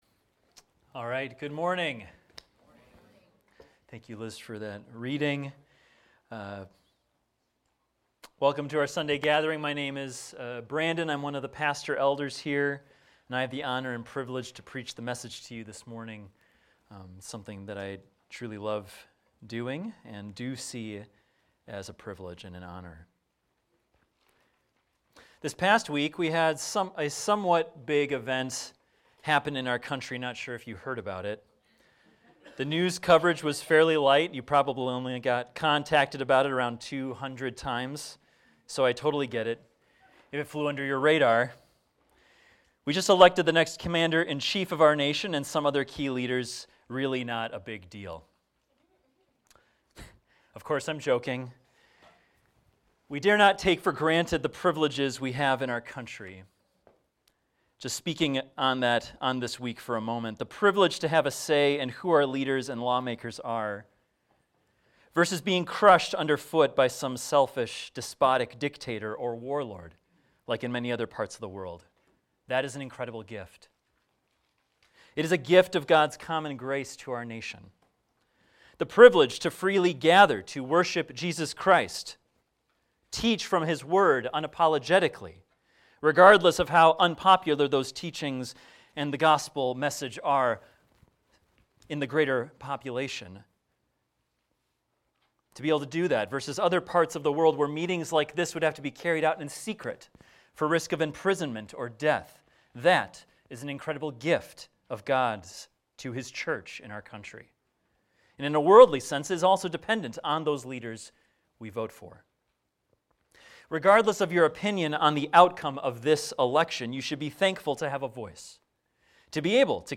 This is a recording of a sermon titled, "Child of the Promise."